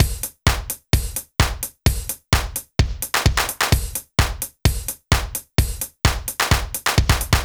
BAL Beat - Mix 2.wav